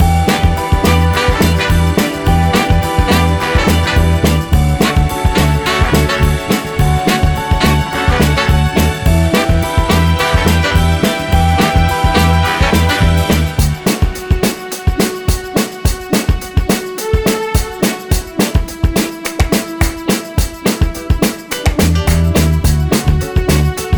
One Semitone Down Pop (2000s) 3:35 Buy £1.50